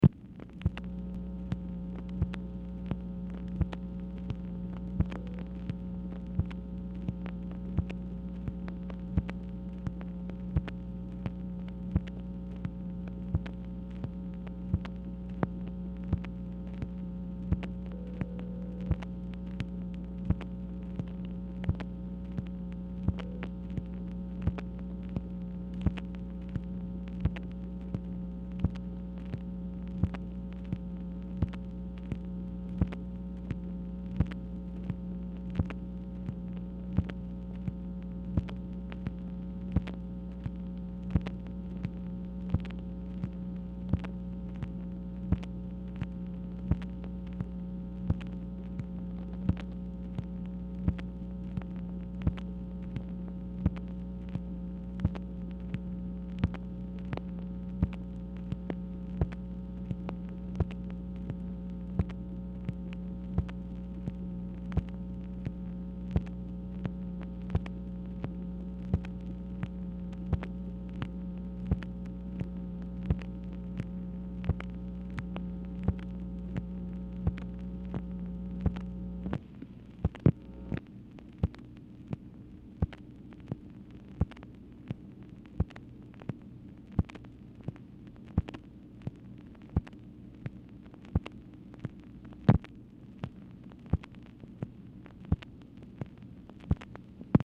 Telephone conversation # 10748, sound recording, MACHINE NOISE, 9/12/1966, time unknown | Discover LBJ
Format Dictation belt